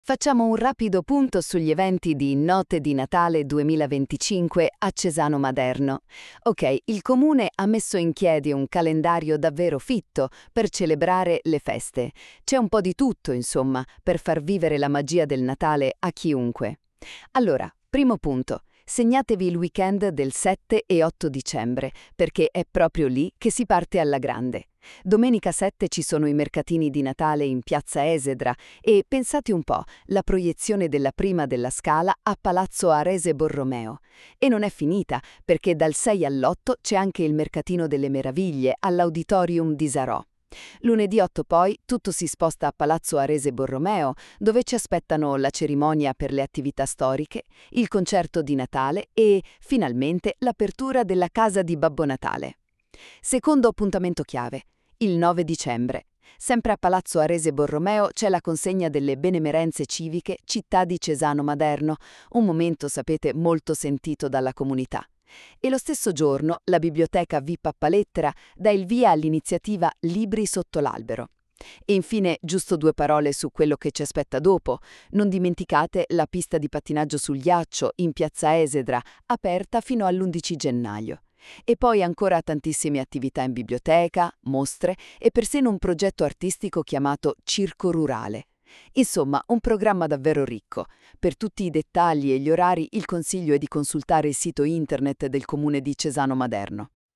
Il podcast è stato realizzato con l’ausilio dell’IA, potrebbe contenere parziali errori nelle pronunce o in alcune definizioni.